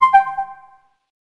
Звук подсказки в обучающей миссии Max Payne